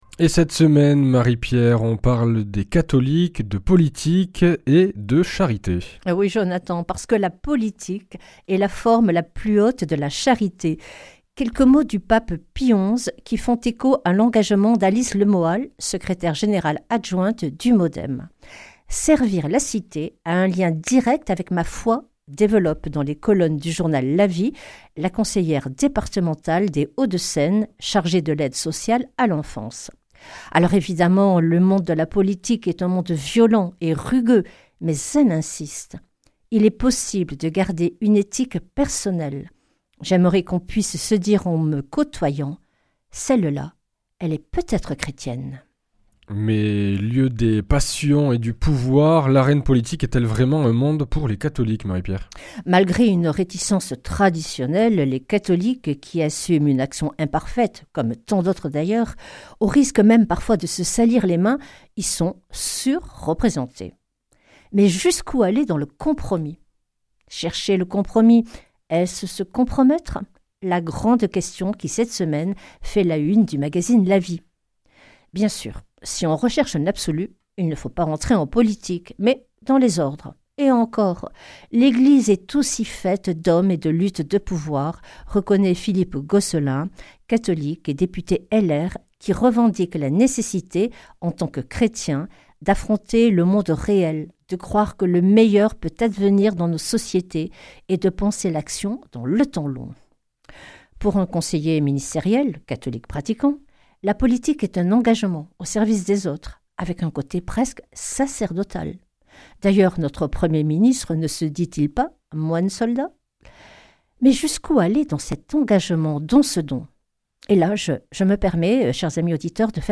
Revue de presse